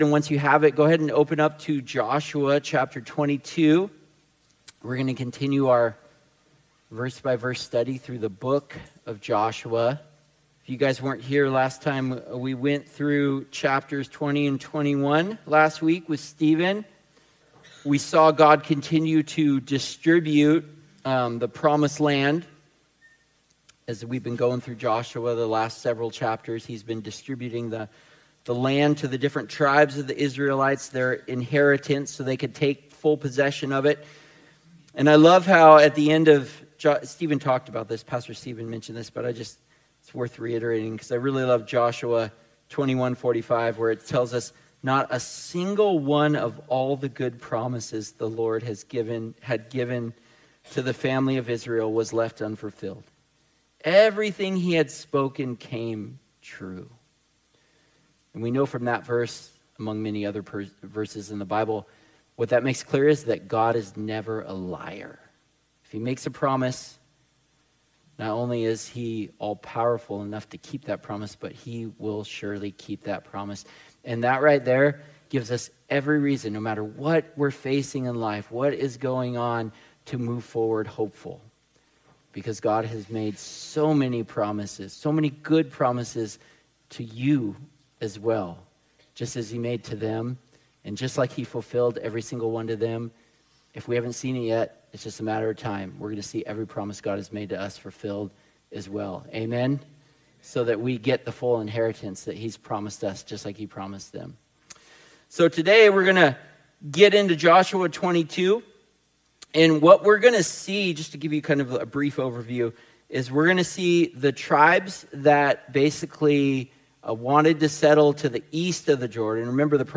Sermons | Coastline Christian Fellowship